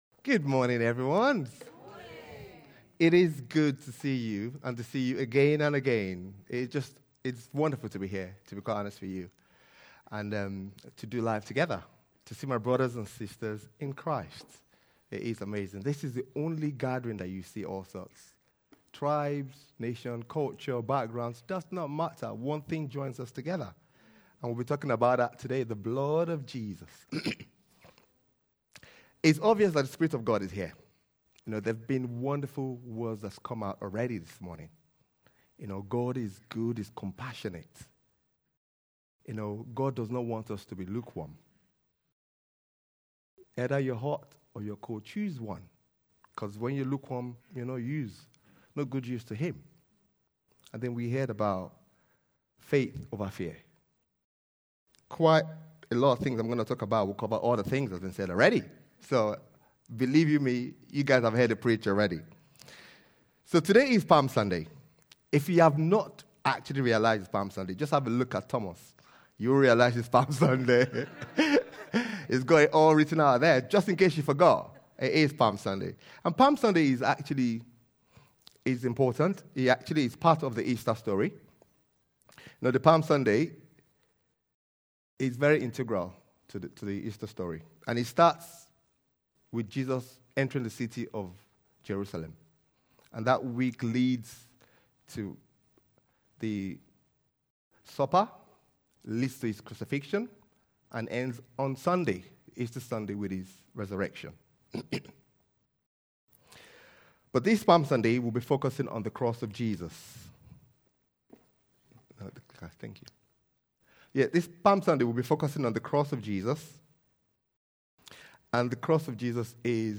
Other Sermons 2025